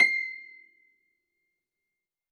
53r-pno22-C5.aif